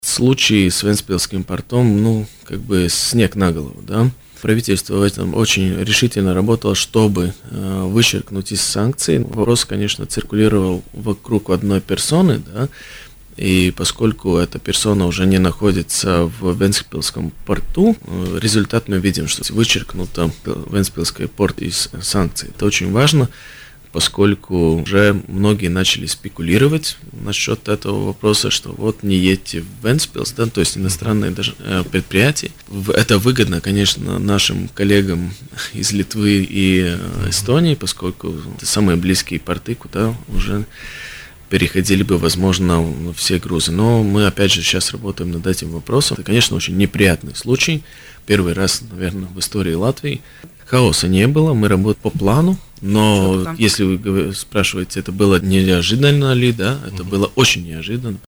Санкции со стороны США в отношении Вентспилсского порта были очень неожиданными и являются первым подобным случаем в истории Латвии.  Об этом в эфире радио Baltkom сказал министр экономики Ральф Немиро.